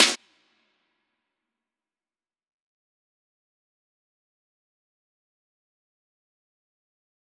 DMV3_Snare 18.wav